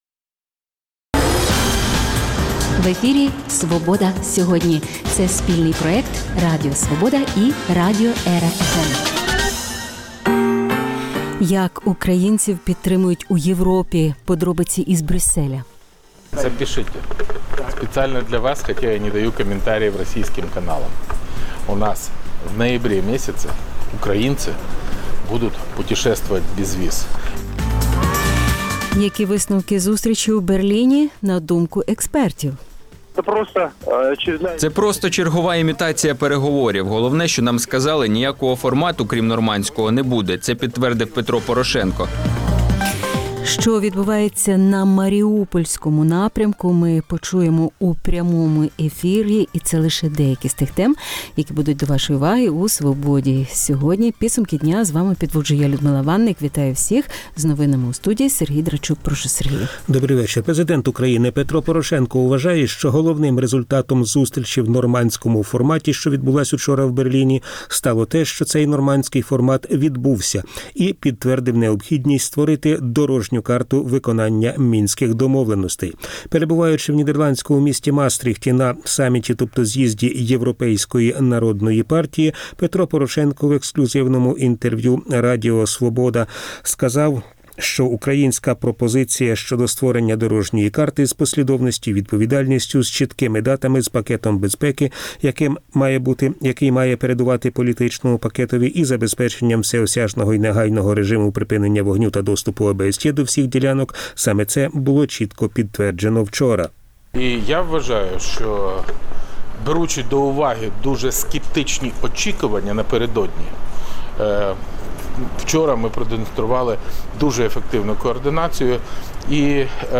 Які висновки зустрічі у Берліні, на думку експертів? Що відбувається на Маріупольському напрямку – почуємо у прямому ефірі У київському СІЗО помер в’язень. Чому обурені правозахисники?